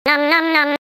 nom-nom-nom-sound-effect.mp3